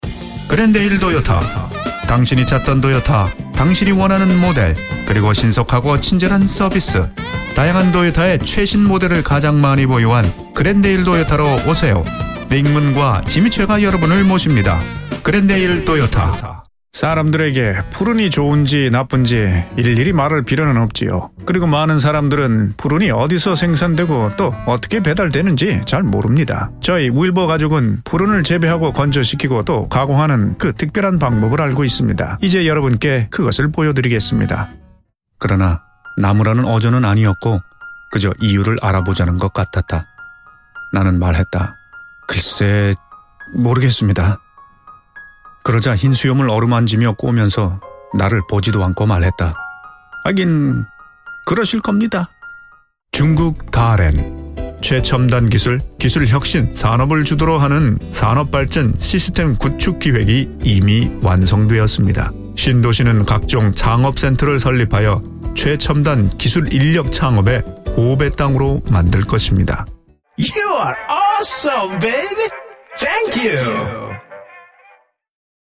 Calm, audio book, authoritive, funny, character voice
Sprechprobe: Werbung (Muttersprache):